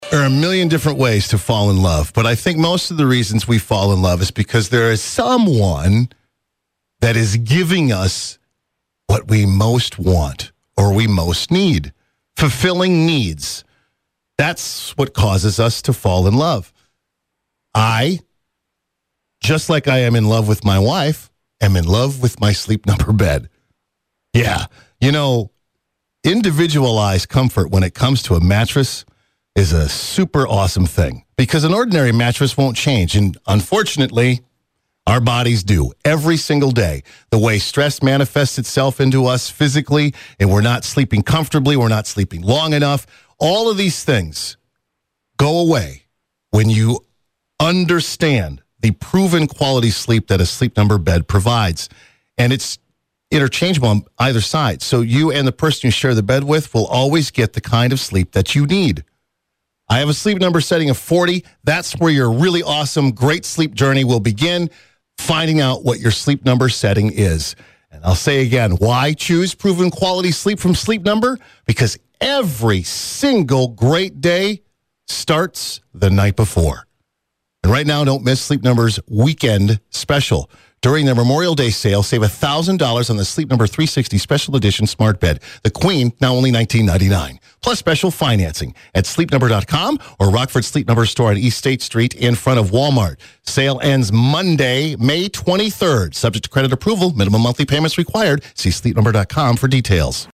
Live reads are ads that are read live on-the-air by a producer or radio host talent.
Creative Examples of Live Read Ads: